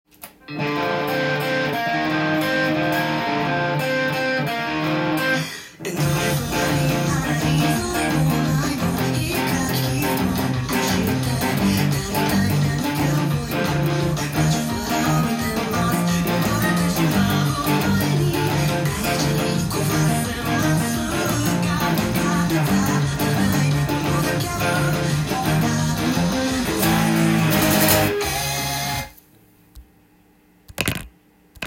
音源にあわせて譜面通り弾いてみました
keyがB♭で殆どがダイアトニックコードで構成されている
エレキギターでカンタンに弾けてしまいます。